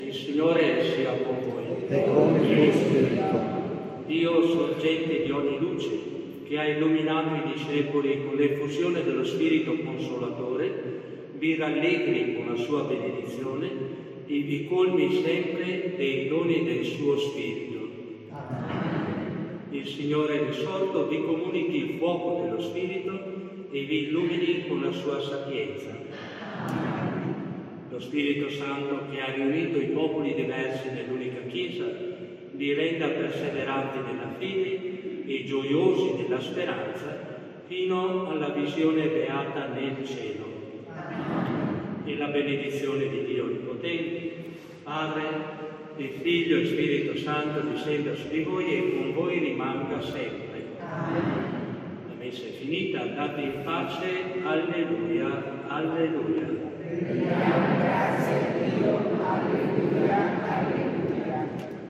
Benedizione-pentecoste-2024.mp3